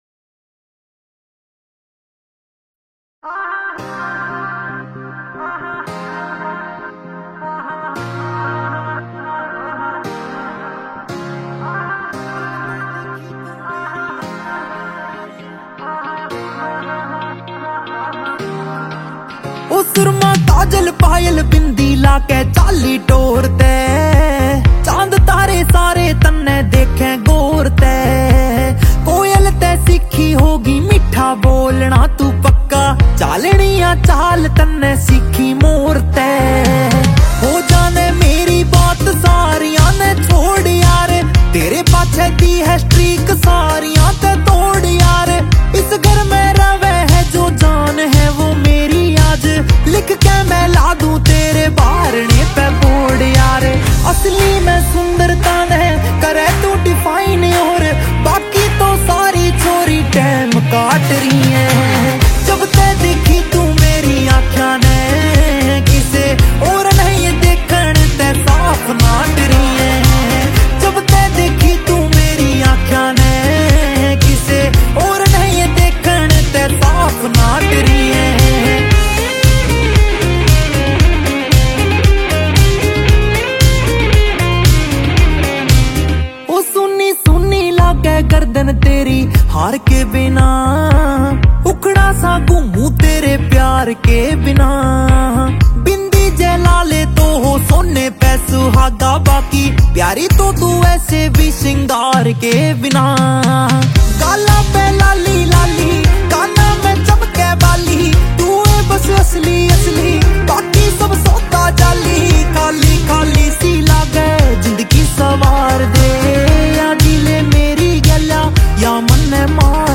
Releted Files Of Latest Haryanvi song